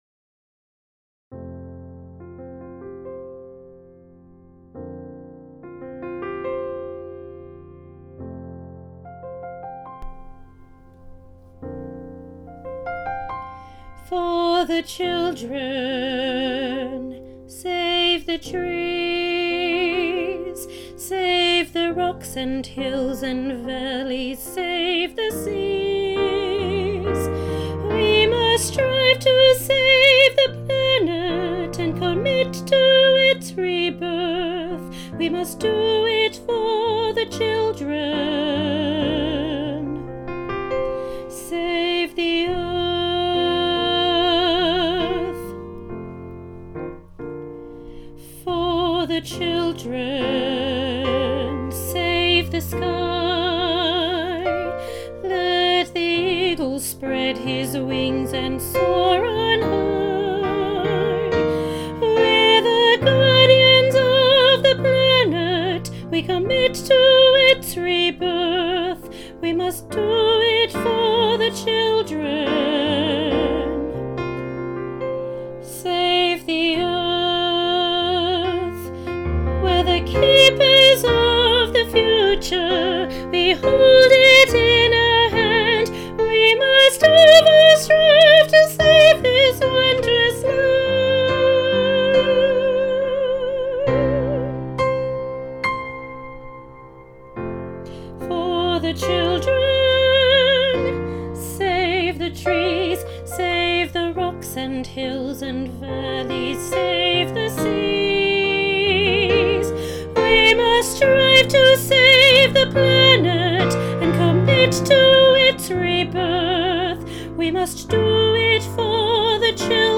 Junior Choir – For The Children, Part 1 With Solo – National Boys Choir of Australia
Junior-Choir-For-the-Children-Part-1-with-solo.mp3